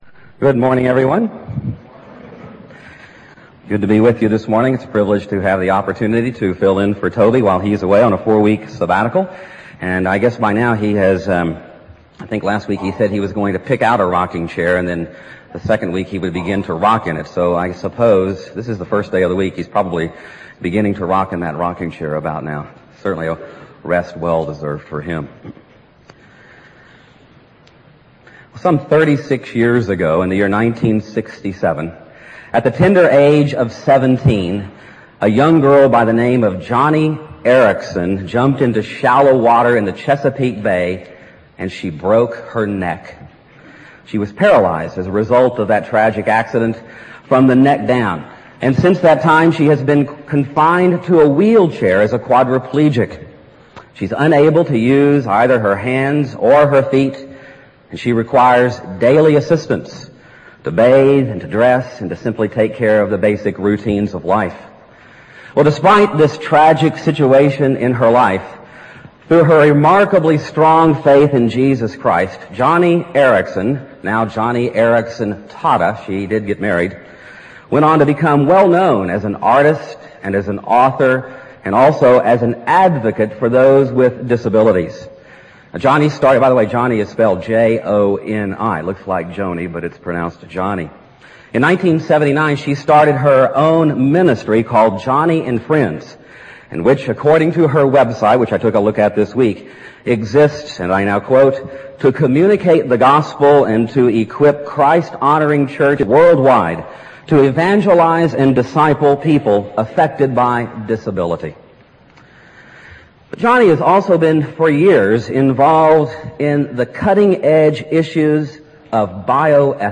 presented as a sermon